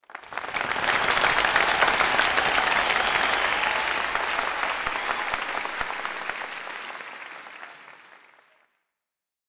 Applause Sound Effect
Download a high-quality applause sound effect.
applause-10.mp3